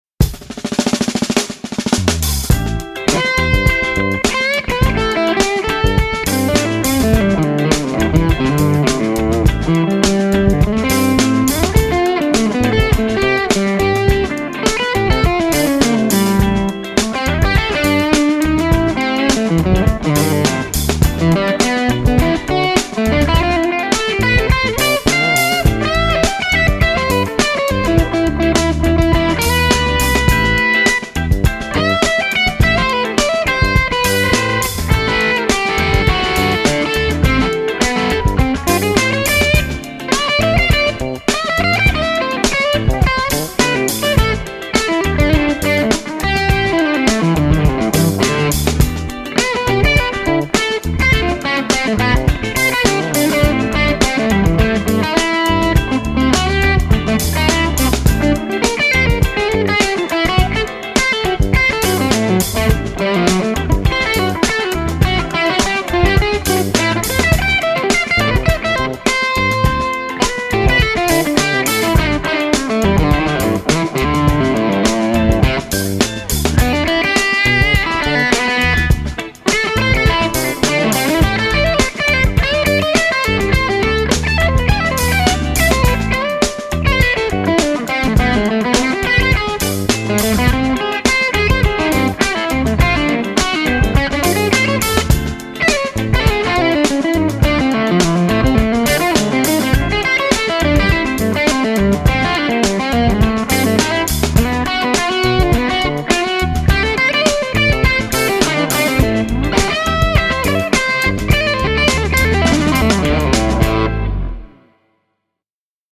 Keep in mind these are close miced with an SM57 and recorded on a home PC. No room tone at all.... The first two clips are the Eminence Red White and Blue speaker.
Glaswerks SOD50 w/HRM on and no post OD treb bleed (33k slope and 330pf treb cap)